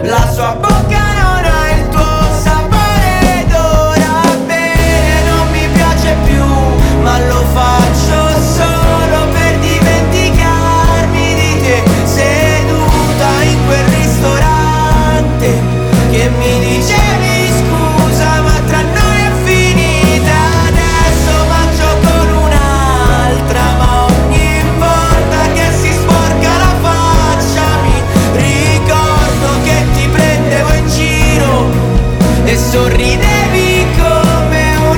Жанр: Поп музыка / Альтернатива
Indie Pop, Alternative